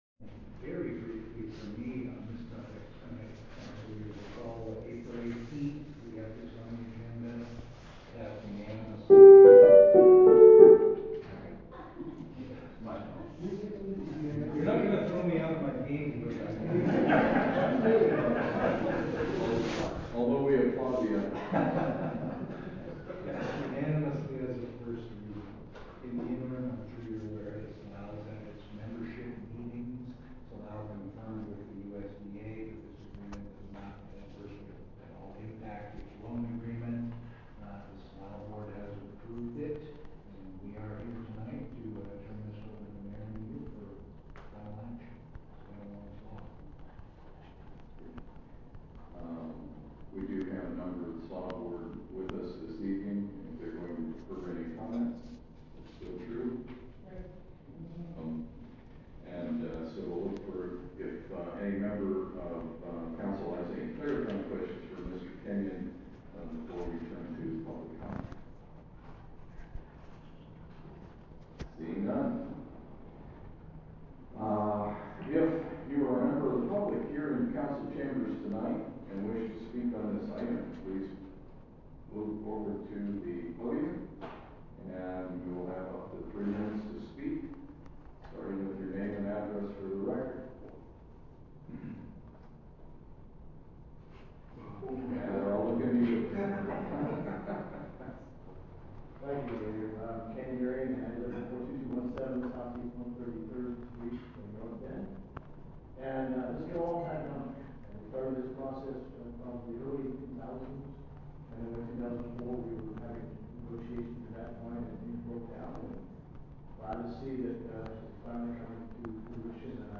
Meeting Audio - June 6, 2023 Part 2 - Technical Difficulties Brief Description - Audio courtesy of Snoqualmie Valley Record